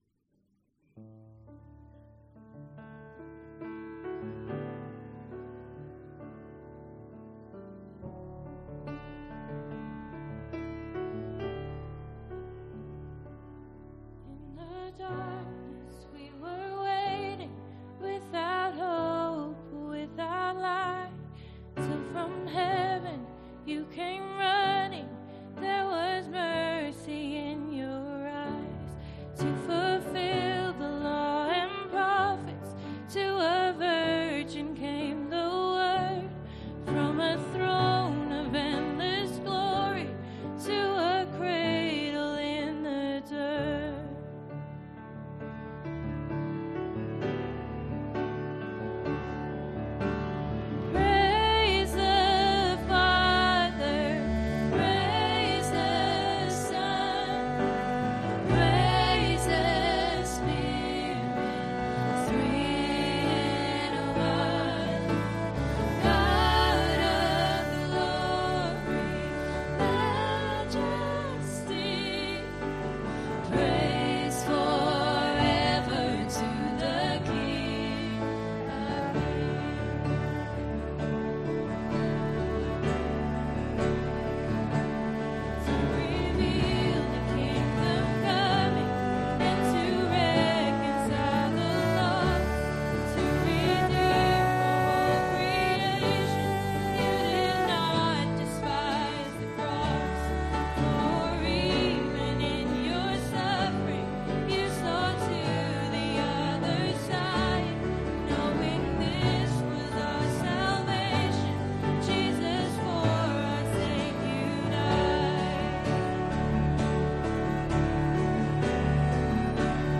Ministry Song https